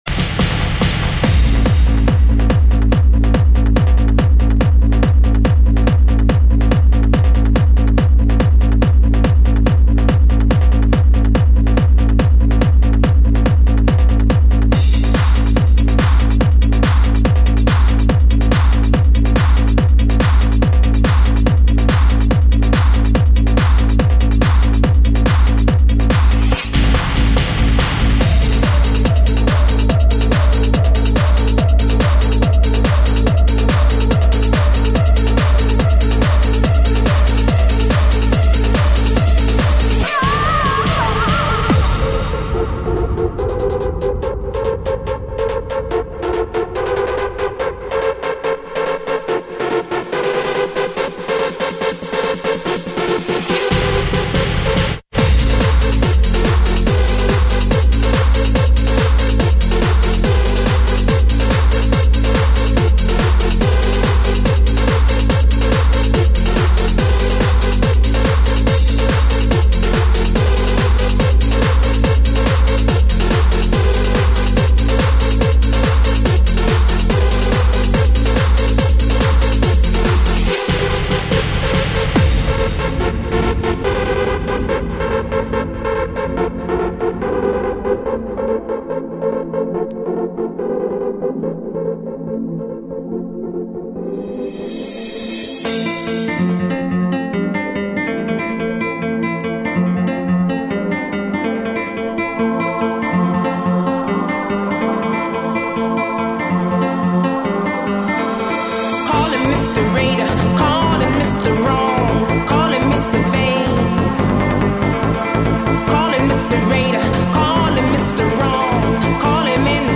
Жанр:Super/Club/Dance